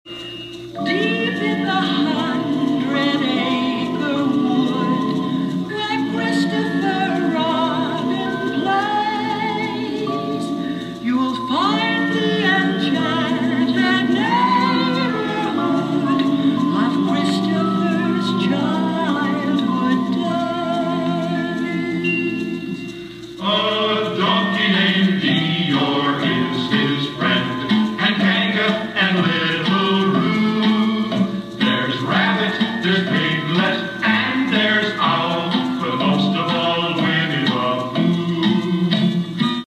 introduction with the iconic theme song